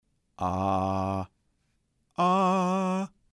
La vocal [a] en tono bajo y alto.